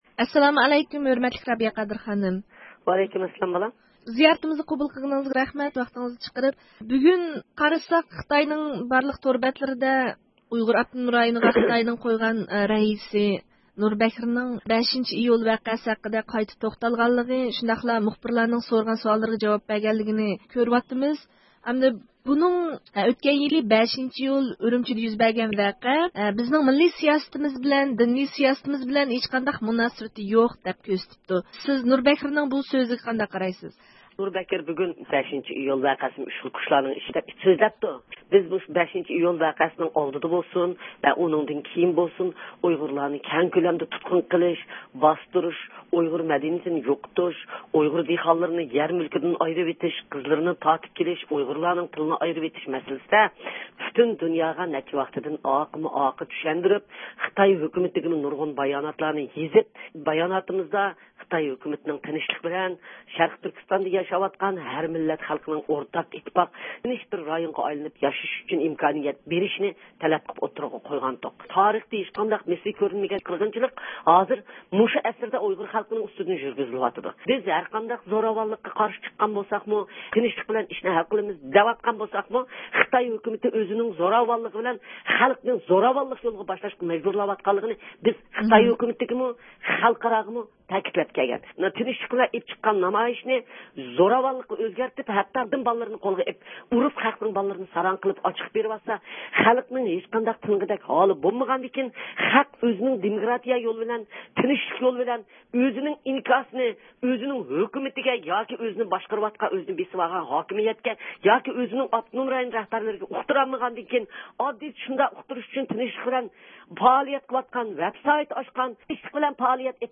بۈگۈن ئۇيغۇر مىللىي ھەرىكىتى رەھبىرى رابىيە قادىر خانىم ئىستانسىمىزنىڭ زىيارىتىنى قوبۇل قىلىپ، نۇر بەكرىنىڭ ئېيتقانلىرىغا كۈچلۈك رەددىيە بەردى ھەمدە نۇر بەكرىنىڭ بايانلىرىنىڭ ئۇيغۇر ئېلى ۋەزىيىتىنىڭ يەنىمۇ جىددىيلىشىشىدىن دېرەك بېرىدىغانلىقىنى، ئۆزىنىڭ ئۇيغۇرلار ۋەزىيىتىدىن ئەندىشە قىلىۋاتقانلىقىنى بىلدۈردى.
يۇقىرىدىكى ئاۋاز ئۇلىنىشىدىن، رابىيە قادىر خانىم بىلەن ئۆتكۈزگەن سۆھبىتىمىزنىڭ تەپسىلاتىنى ئاڭلايسىلەر.